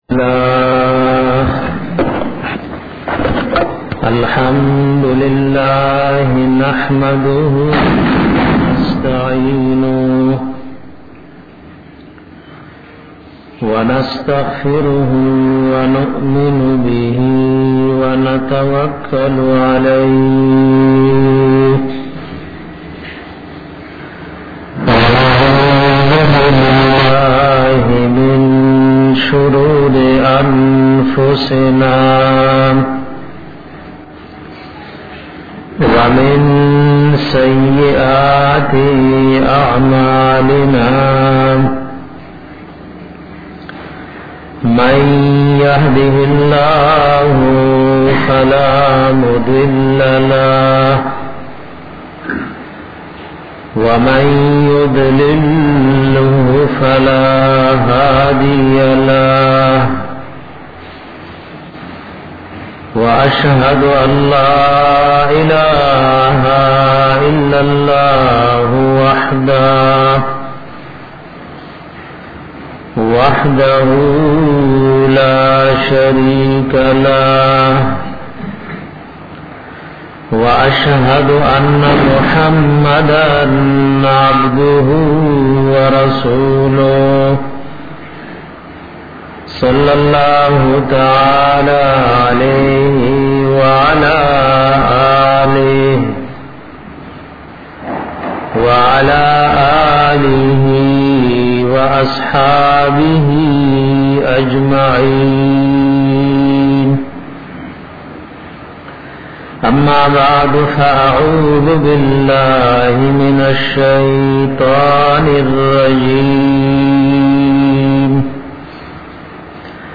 bayan pa bara da ajizai da nabi s a w